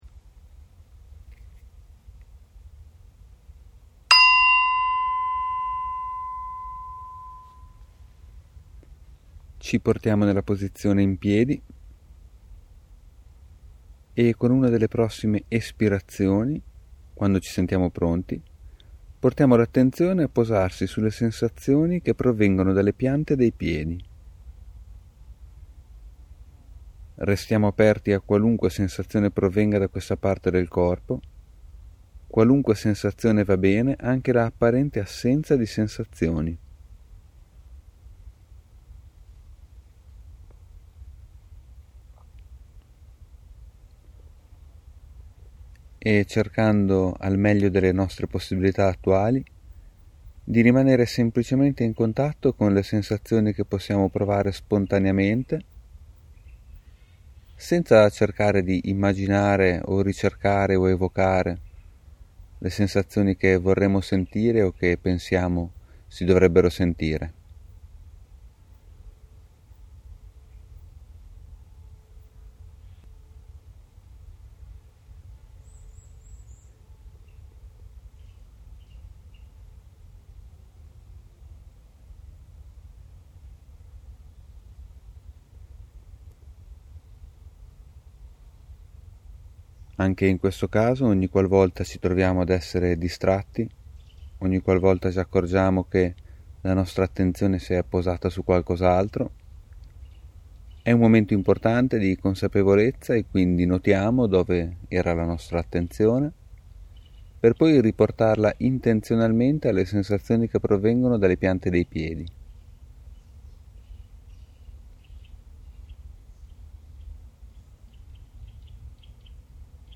Le prime volte che si pratica è vivamente consigliato utilizzare le audioguide, non contengono molte parole, ma sono molto utili per mantenere ben a mente l’intenzione e alcuni passaggi importanti.
Almeno per le prime volte è consigliato utilizzare l’audioguida.